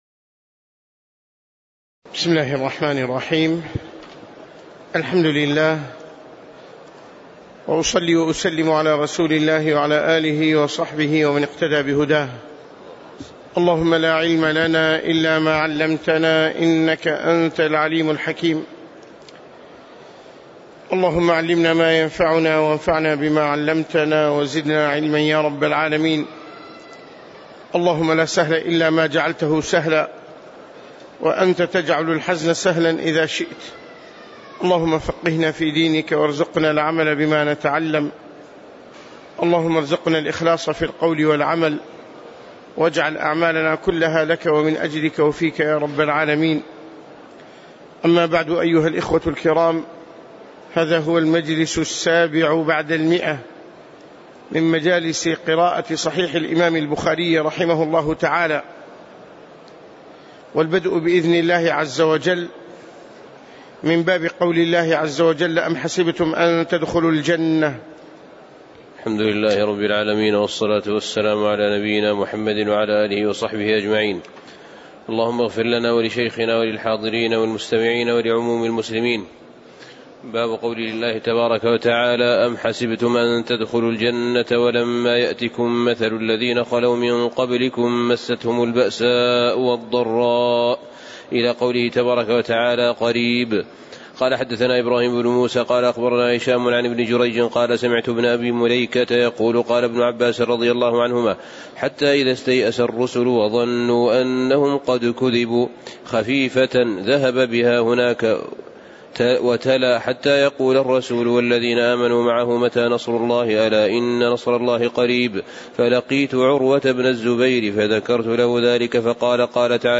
تاريخ النشر ٢٨ رجب ١٤٣٨ هـ المكان: المسجد النبوي الشيخ